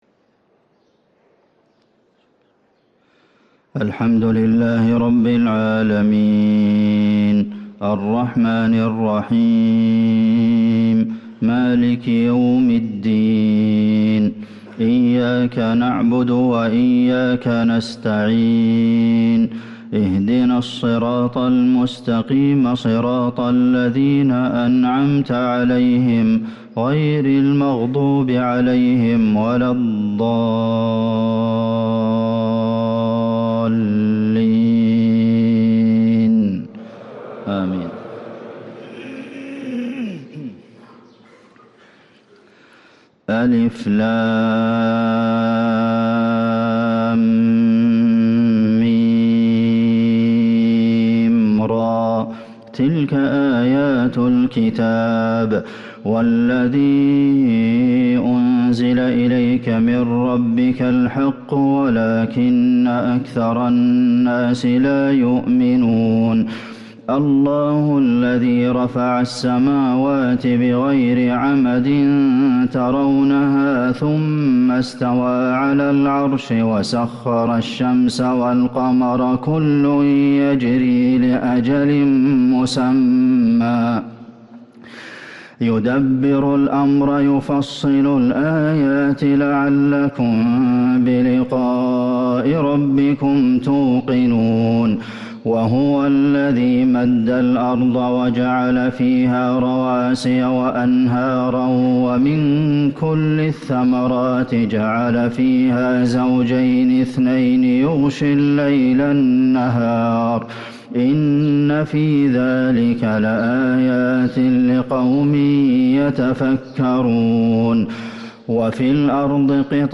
صلاة الفجر للقارئ عبدالمحسن القاسم 8 جمادي الأول 1445 هـ
تِلَاوَات الْحَرَمَيْن .